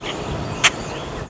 Common Grackle diurnal flight calls